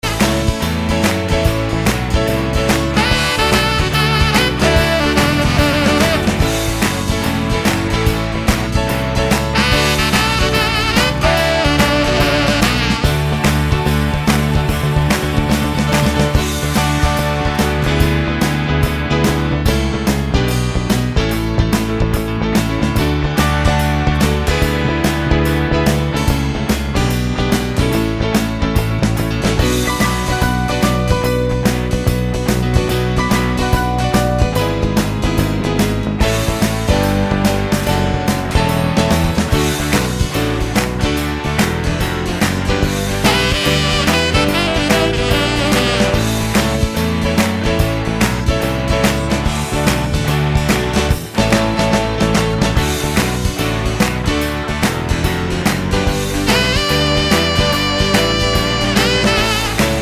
no Backing Vocals Pop (1970s) 2:56 Buy £1.50